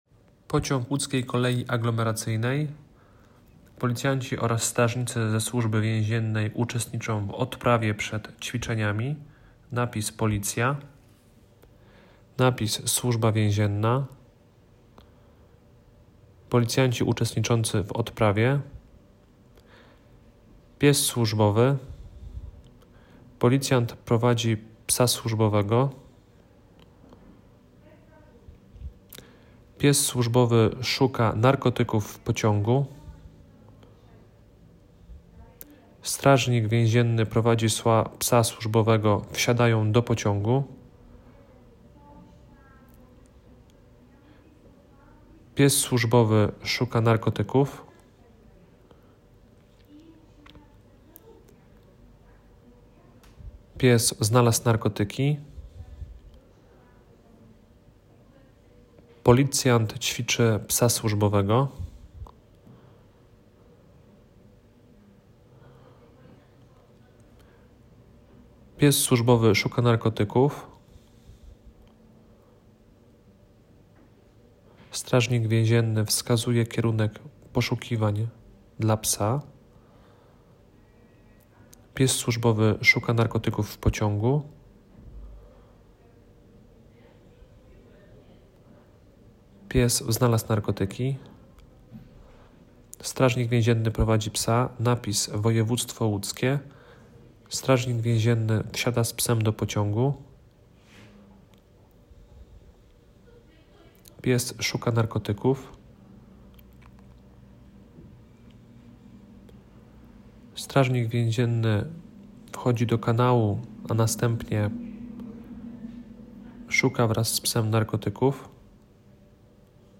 22 stycznia 2025 roku, na terenie zaplecza technicznego Łódzkiej Kolei Aglomeracyjnej w Łodzi, przewodnicy ze swoimi czworonożnymi partnerami, doskonalili umiejętności, wykorzystywane podczas sprawdzania środków transportu zbiorowego oraz pomieszczeń technicznych, pod kątem ukrycia środków odurzających.
Nagranie audio Szkolenie_psow_sluzbowych.m4a